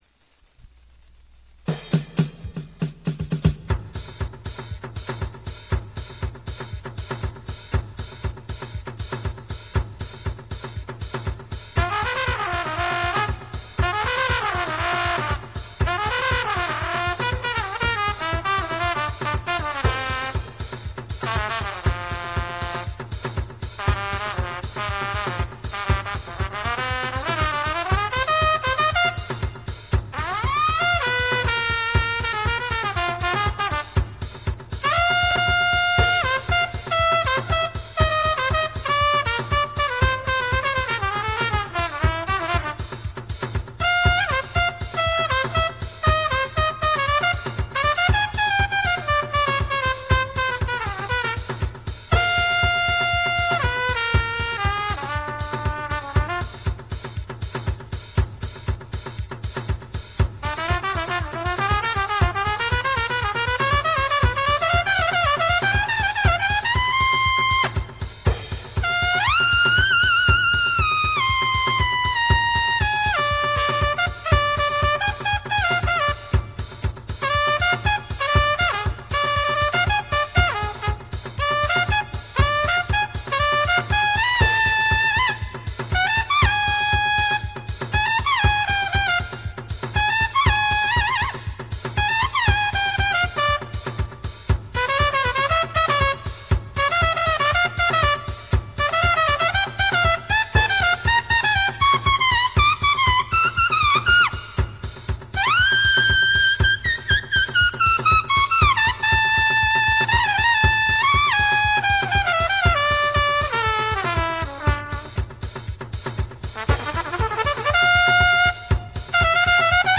As a final comment on these mouthpieces, I have recorded a jump and jive swing tune, "The Asymmetric Jump" shown as a RealAudio file below. This is a 3 minute groove/cadenza (whatever!) that is features just trumpet and drums in the style of Harry James and Gene Krupa. The Lynch Asymmetric was used throughout.
RealAudio has a characteristic noise that is evident here.